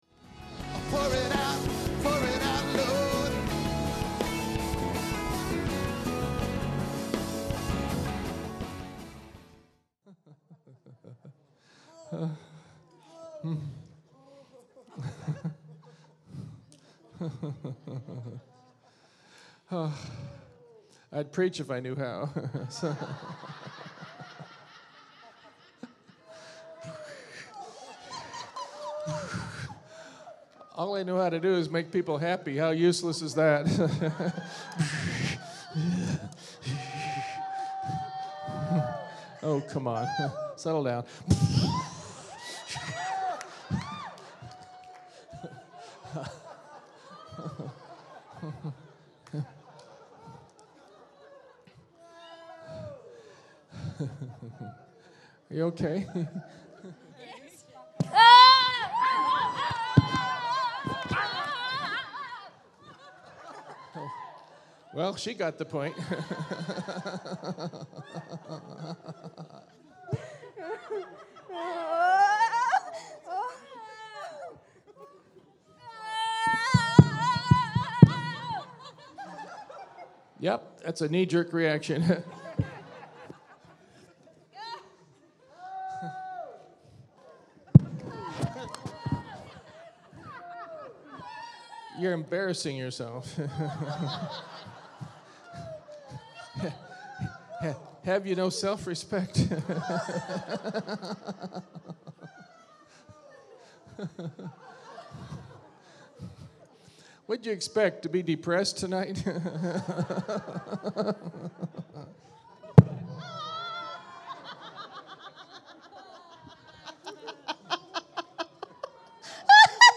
Preaching at Pour It Out Church | Sunshine Coast.